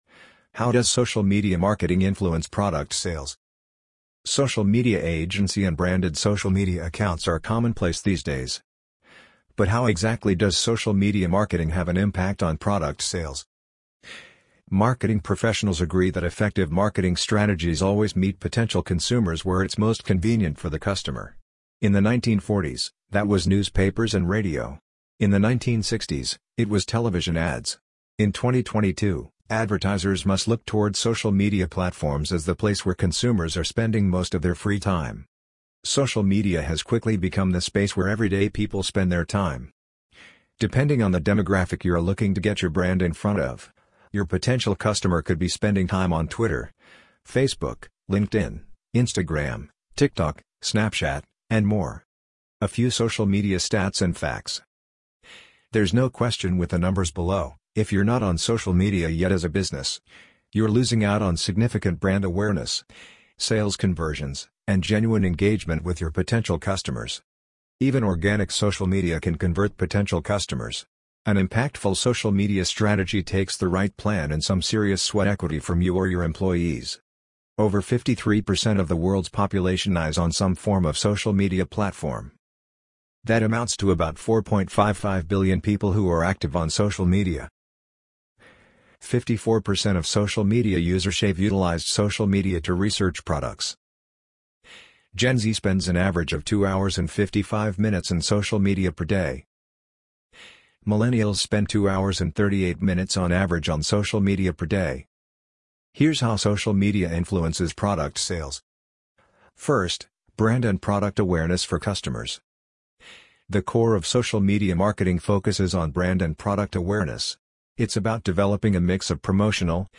amazon_polly_7222.mp3